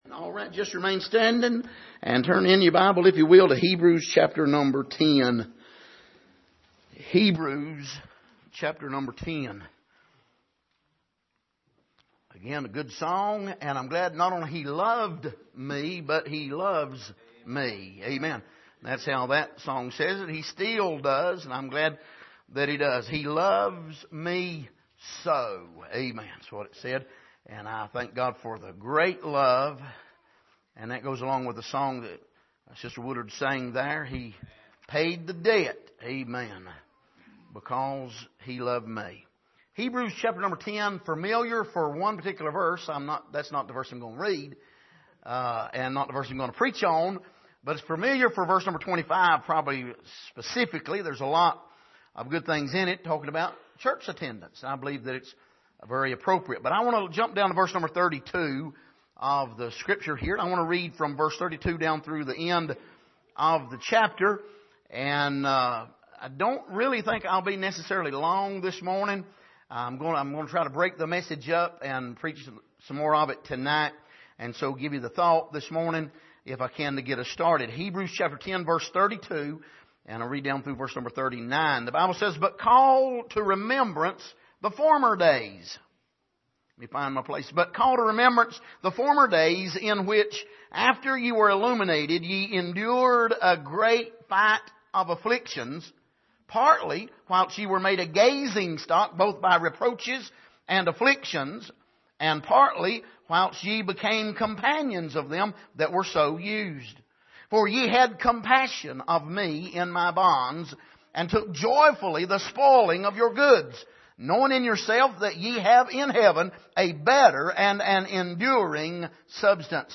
Passage: Hebrews 10:32-39 Service: Sunday Morning